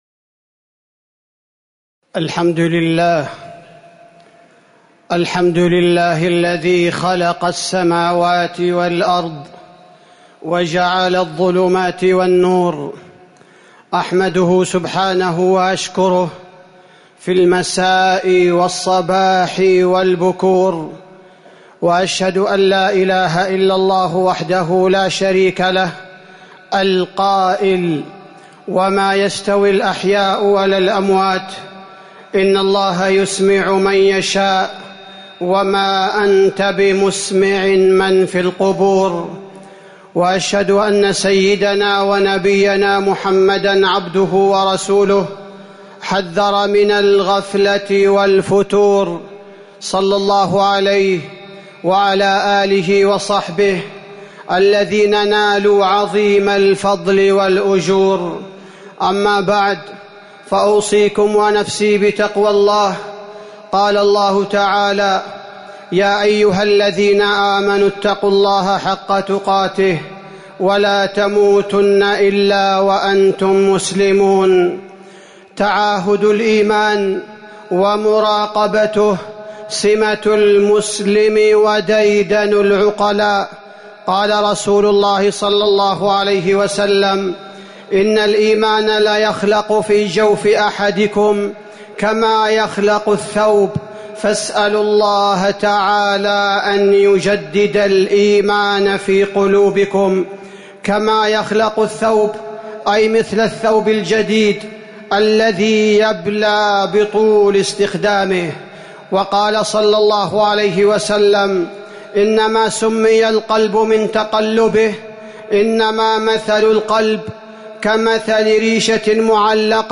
تاريخ النشر ١ جمادى الأولى ١٤٤١ هـ المكان: المسجد النبوي الشيخ: فضيلة الشيخ عبدالباري الثبيتي فضيلة الشيخ عبدالباري الثبيتي تجديد الإيمان